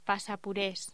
Locución: Pasapurés
voz